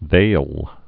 (thāl)